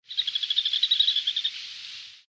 Dot-winged Crake (Laterallus spiloptera)
Sex: Indistinguishable
Location or protected area: Reserva Natural Punta Rasa
Condition: Wild
Certainty: Recorded vocal